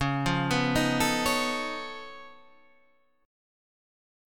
Db+9 chord